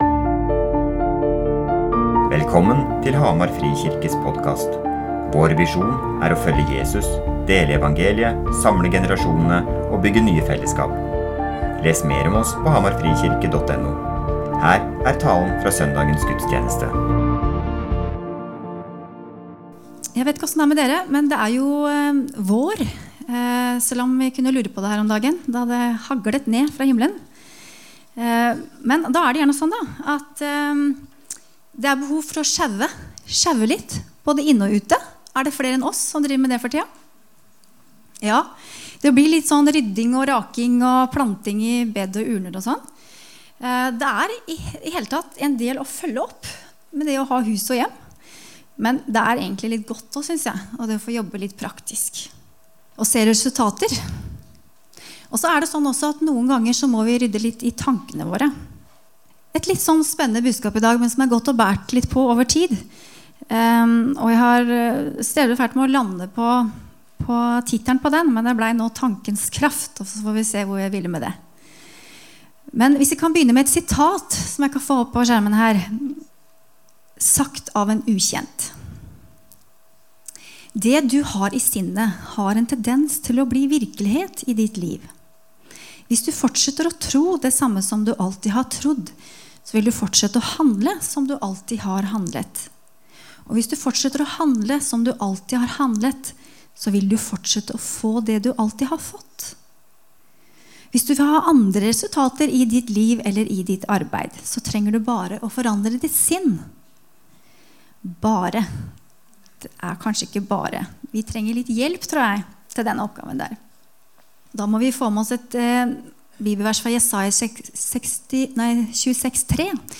Hjerte Gudstjenesten https